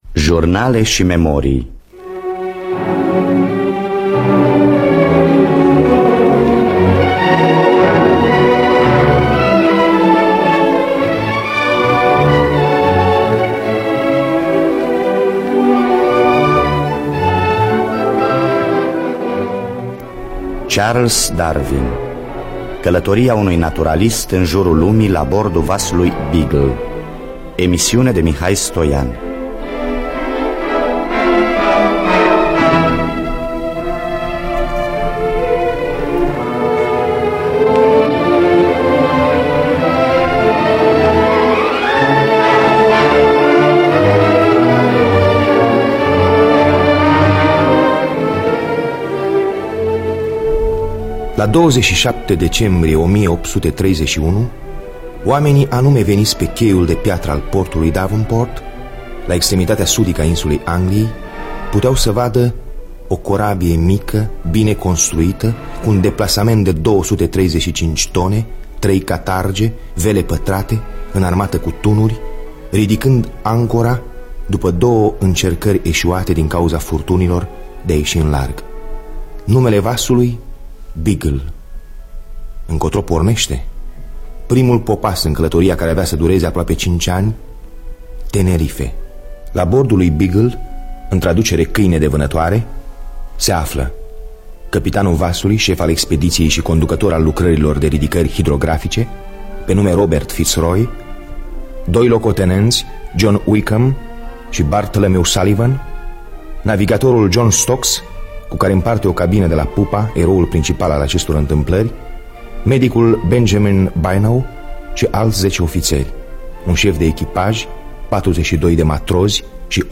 Scenariu radiofonic de Mihai Stoian.